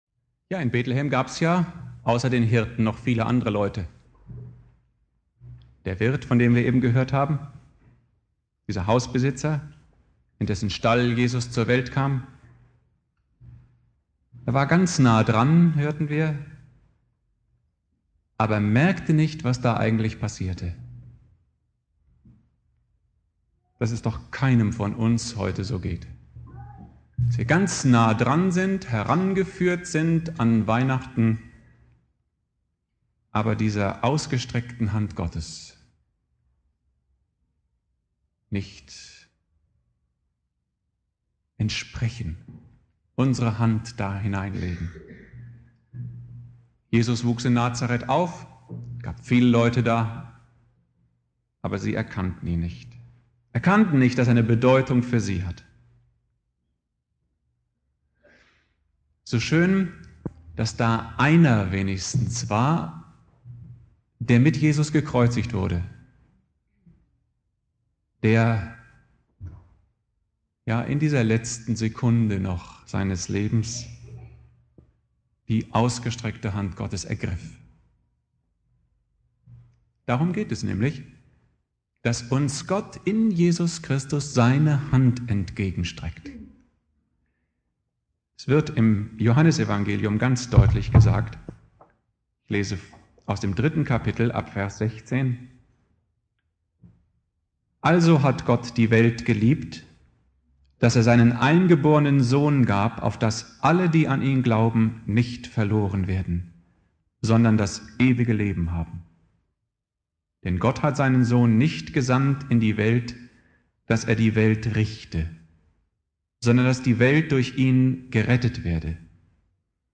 Predigt
Heiligabend